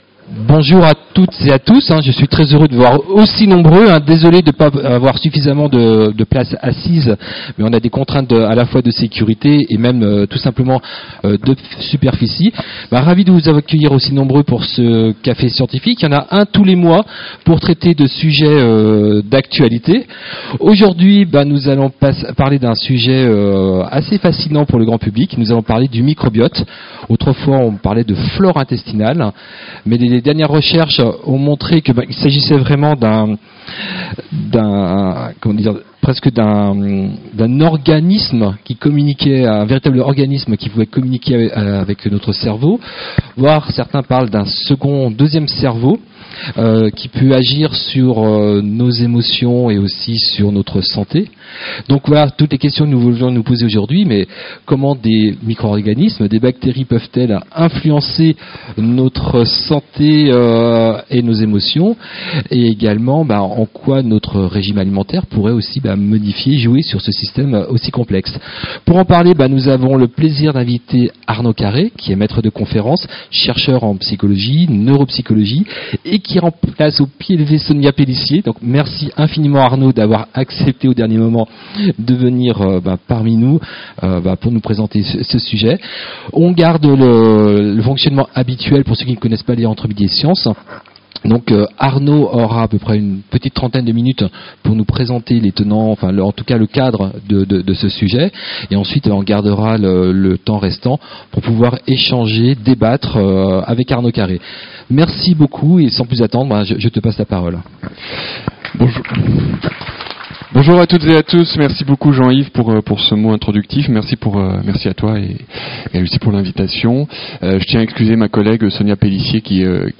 Vous voulez en savoir plus sur ce sujet ? Ecoutez le café-débat et retrouvez toutes les questions du public sur l'enregistrement audio .